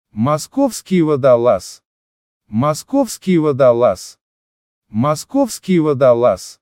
MOSCOW WATER DOG - Moskovsky Vodolaz, Московский водолаз